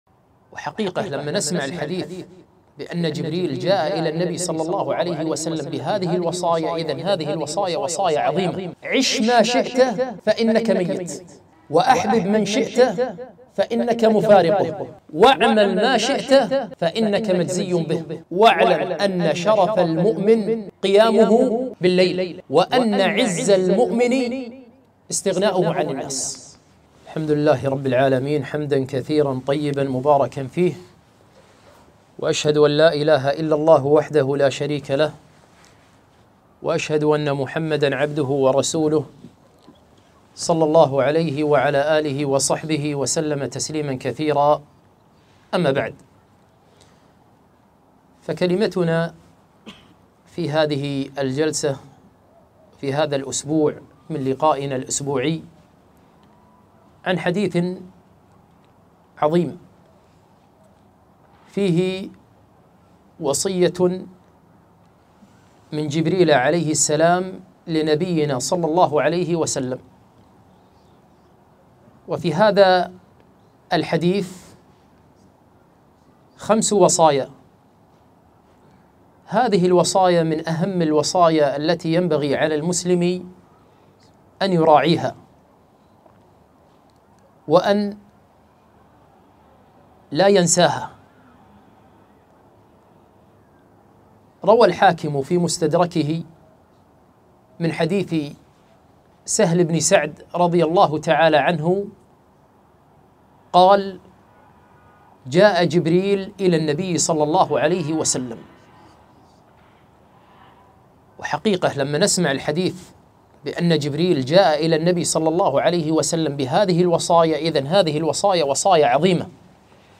محاضرة - وصايا جبريل للنبي صلى الله عليه وسلم